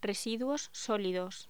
Locución: Residuos sólidos
voz
Sonidos: Voz humana